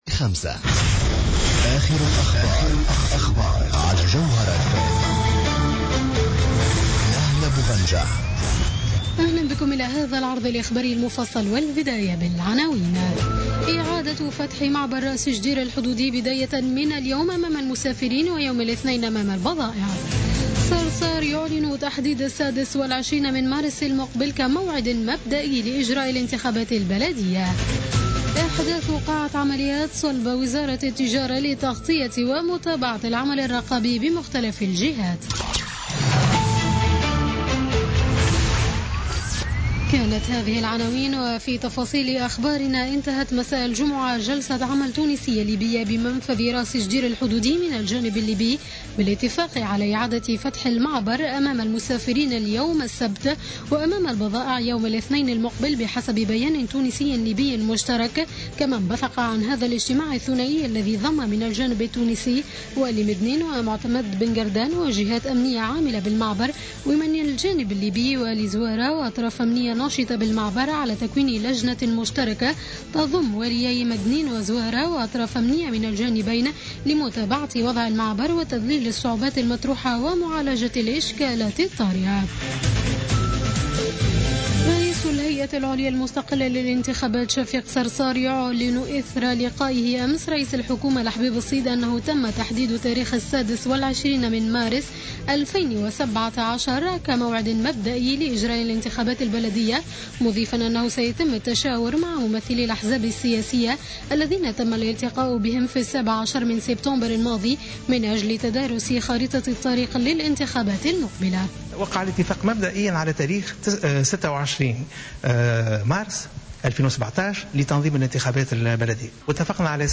نشرة أخبار منتصف الليل ليوم السبت 14 ماي 2016